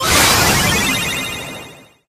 starpower_speed_01.ogg